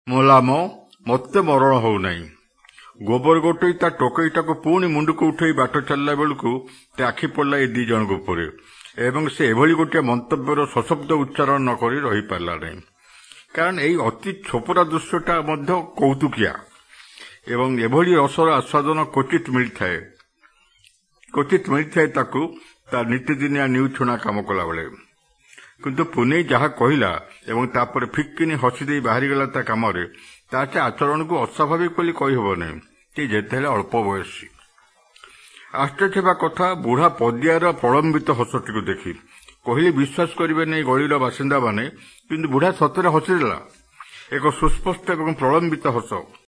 lots of “o” sounds